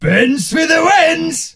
ash_lead_vo_01.ogg